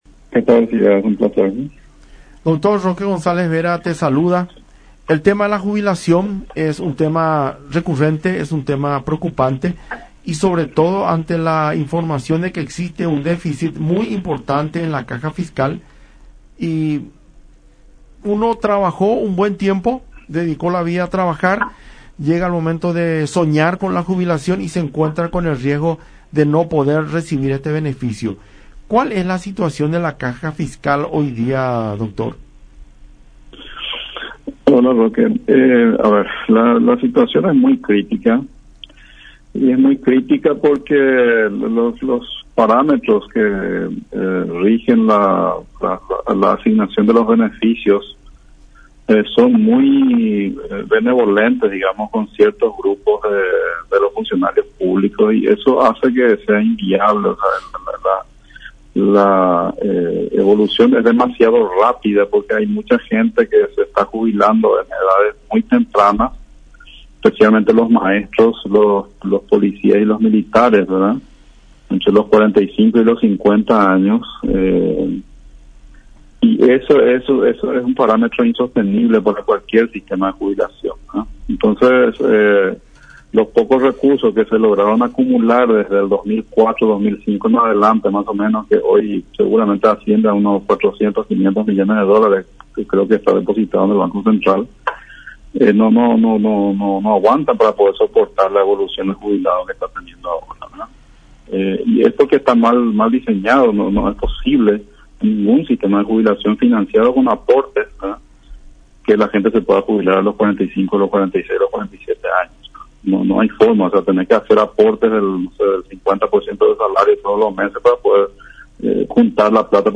Ningún sistema de jubilación financiado con aportes hace que la gente pueda jubilarse ya con 45 años”, explicó Barreto en diálogo con La Unión.